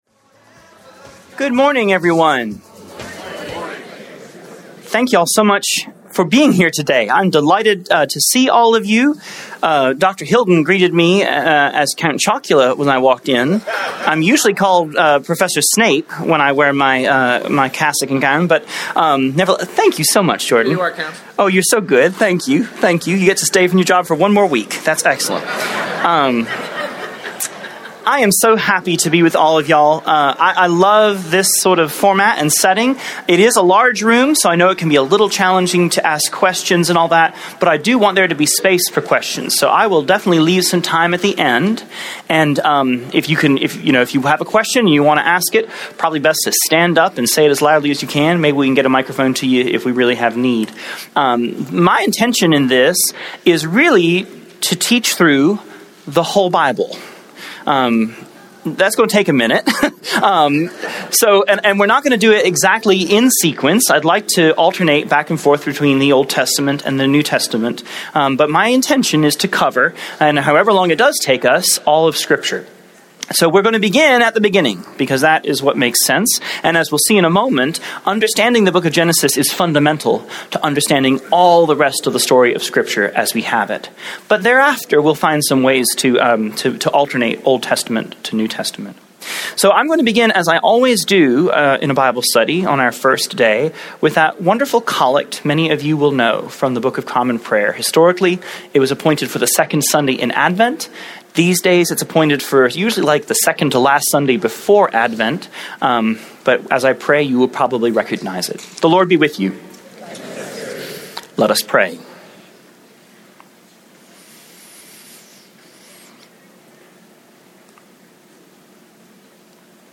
A Bible study explores Creation, the fall, flood and the call of Abraham.